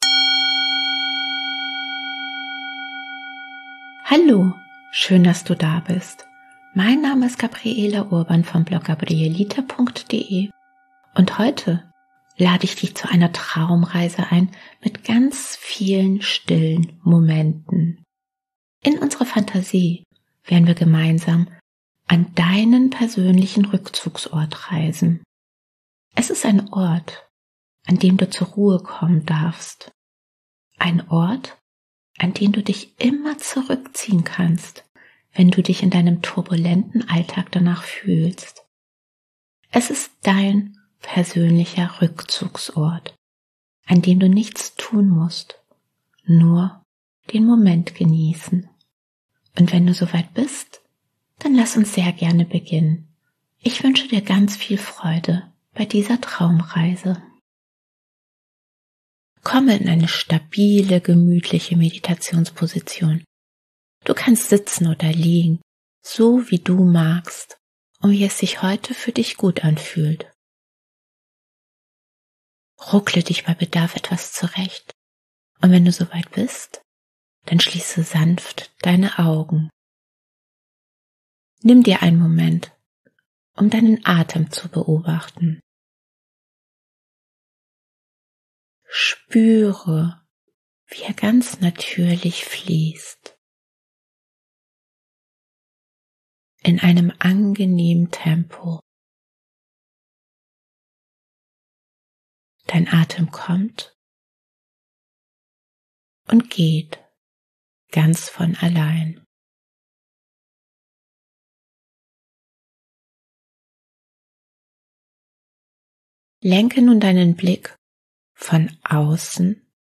Kurze Traumreise an deinen inneren Rückzugsort